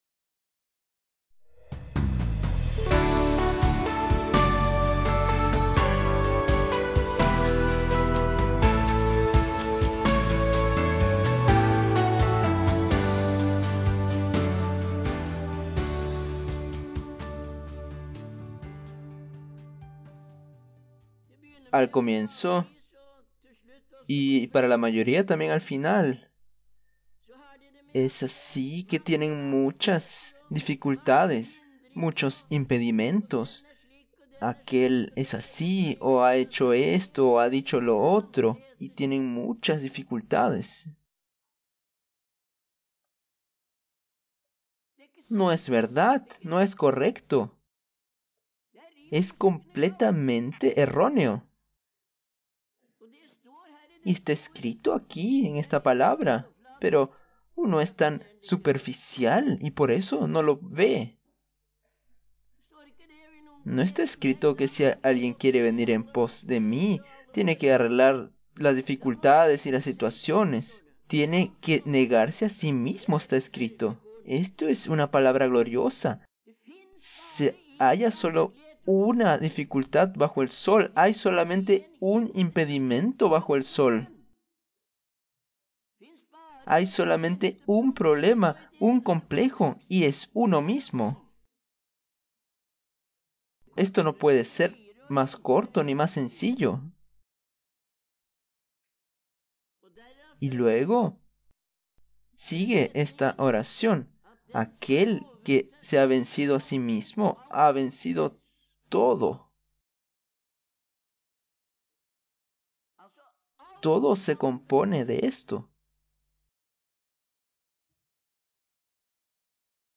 Taleutdrag fra januar 1973